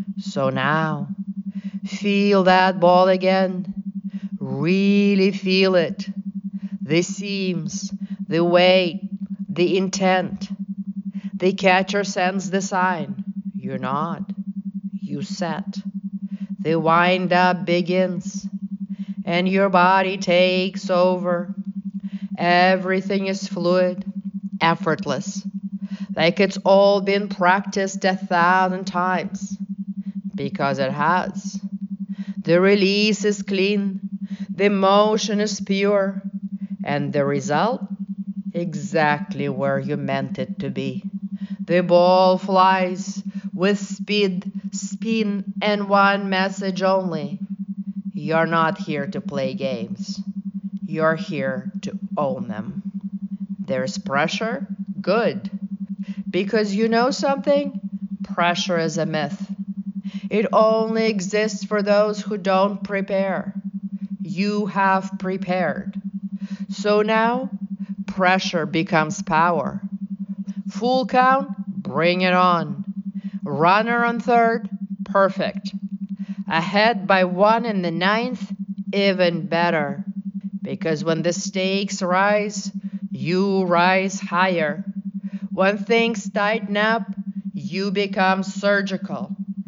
With advanced hypnosis and NLP techniques, this session builds mental resilience, enhances baseball IQ, and hardwires your ability to recover, refocus, an